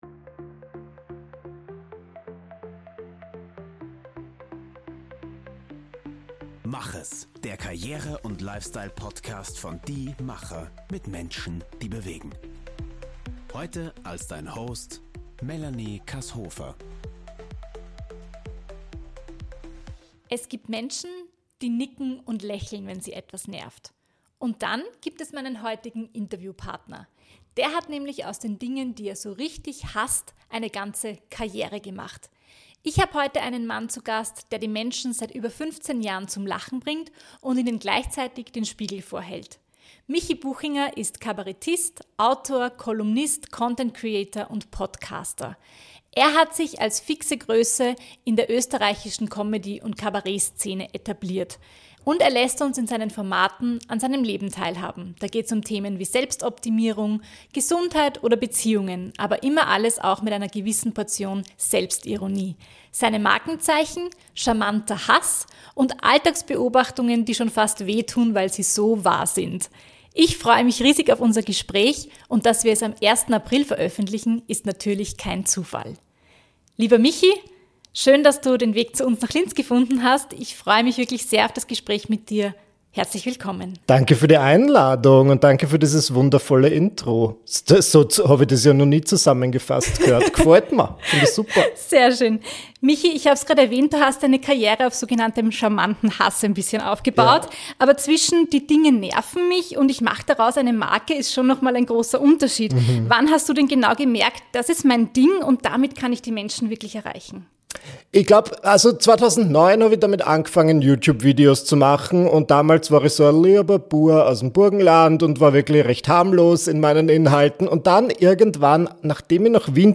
In dieser Folge haben wir einen Mann zu Gast, der die Menschen seit über fünfzehn Jahren zum Lachen bringt und ihnen gleichzeitig den Spiegel vorhält.